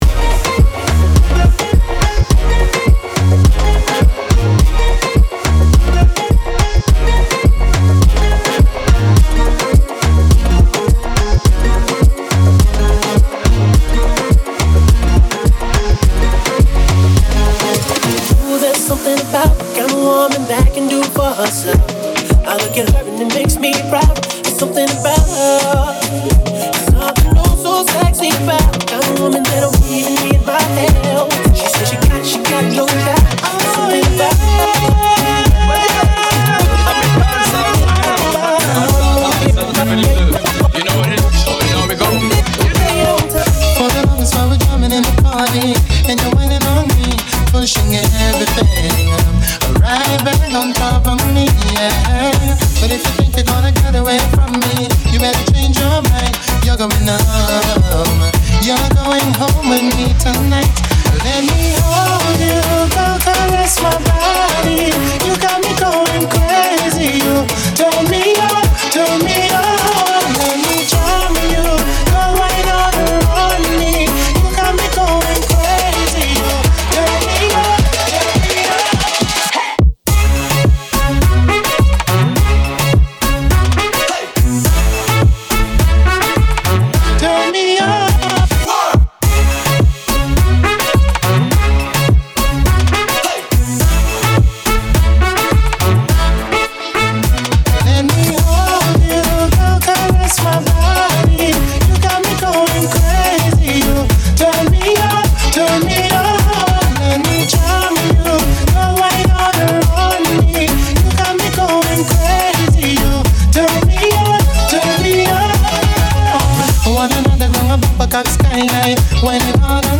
R&B, Moombah